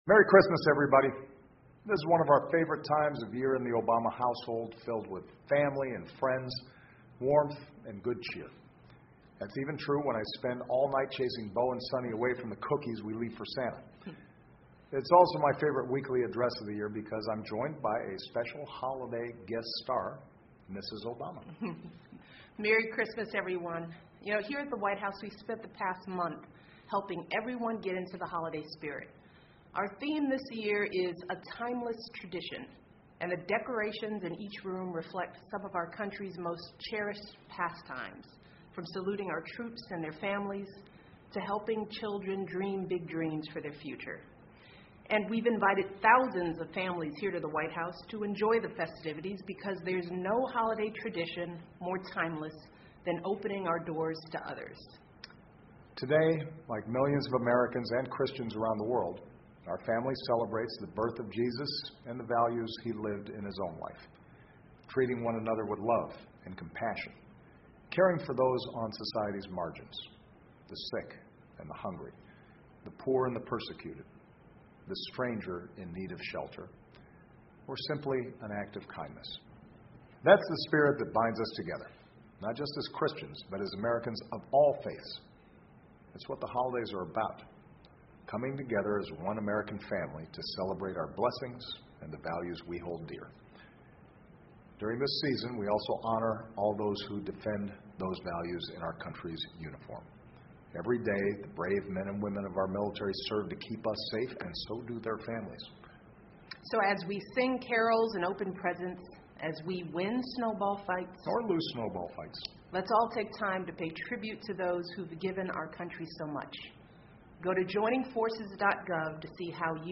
奥巴马每周电视讲话：总统祝福全国人民圣诞节快乐 听力文件下载—在线英语听力室